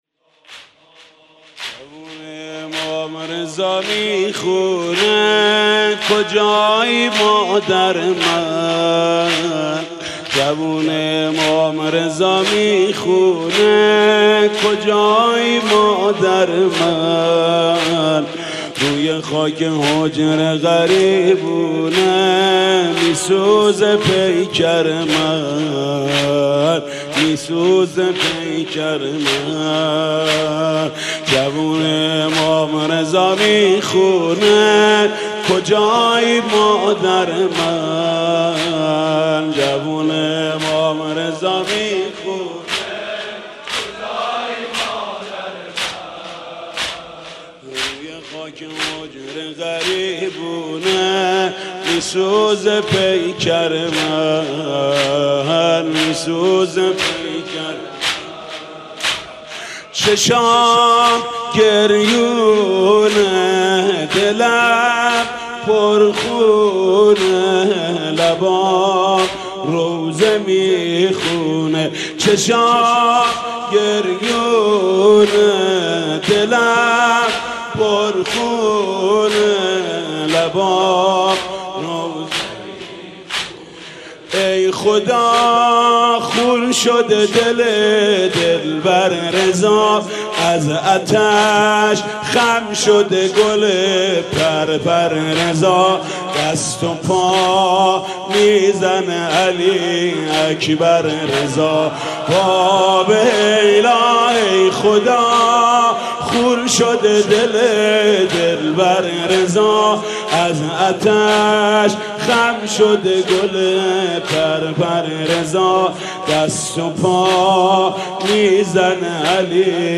مرثیه سرایی حاج محمود کریمی در ایام شهادت امام جواد (ع)